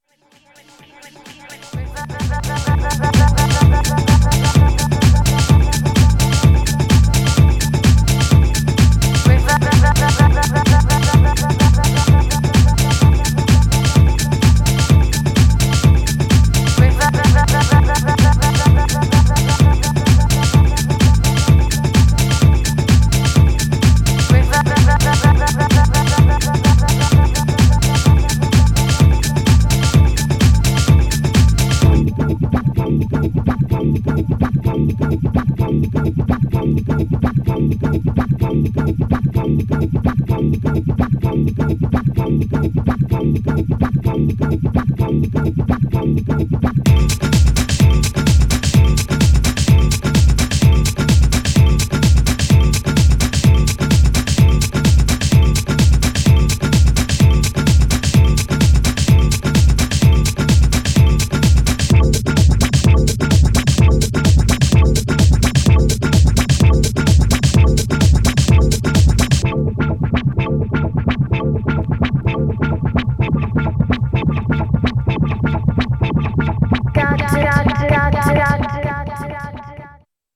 Styl: Progressive, Drum'n'bass, House